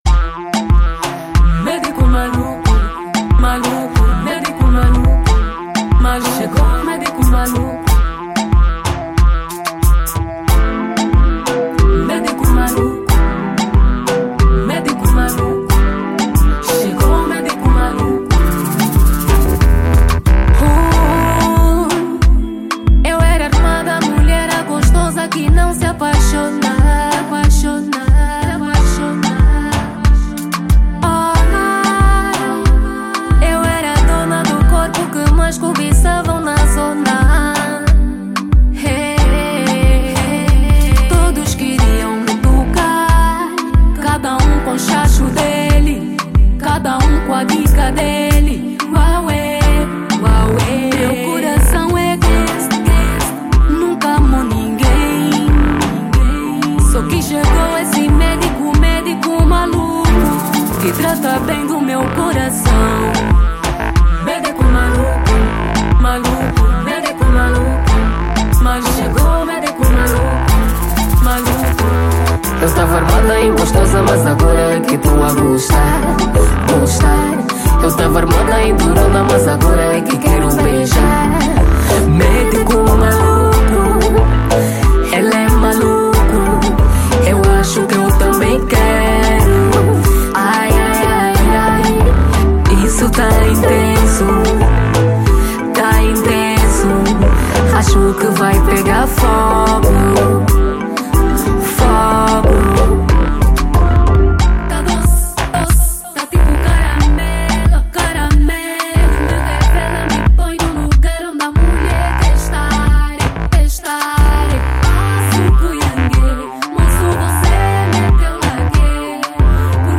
Zouk